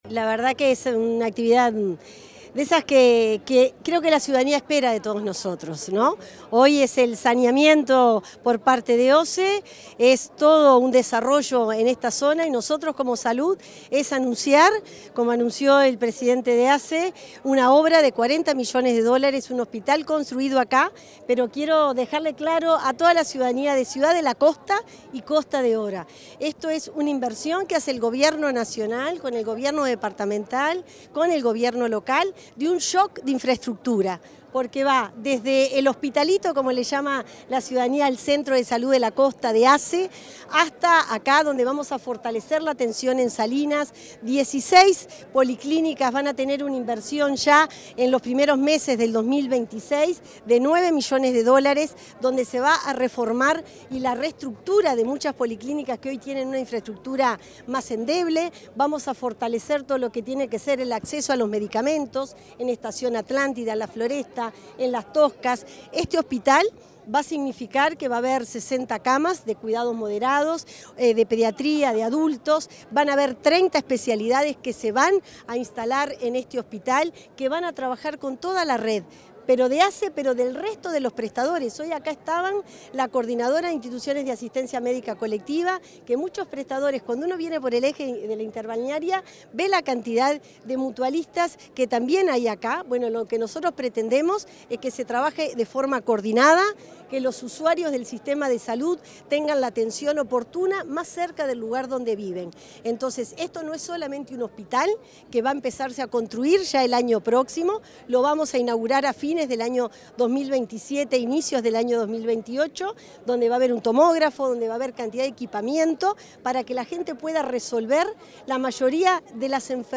Declaraciones de la ministra de Salud Pública, Cristina Lustemberg
La ministra de Salud Pública, Cristina Lustemberg, dialogó con la prensa en Atlántida, sobre el plan Salud Puente a Puente.